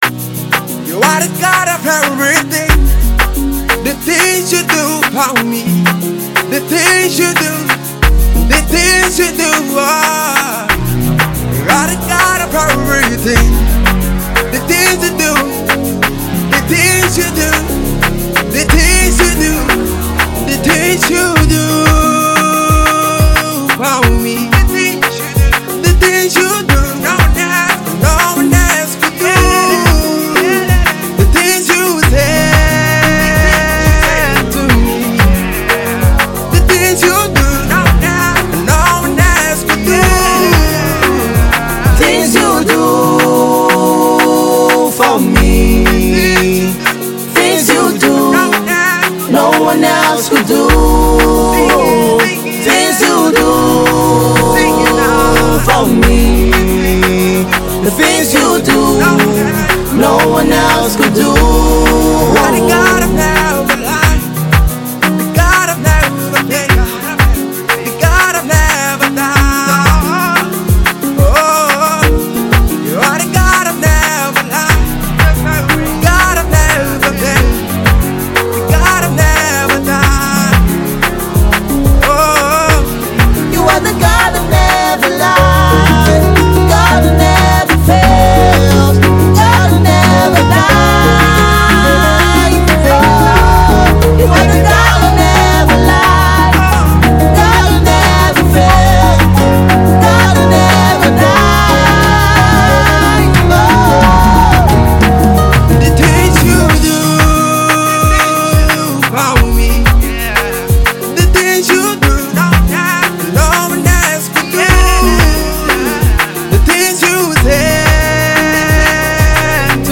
Gospel
This song will get all gospel lovers on their feet!!!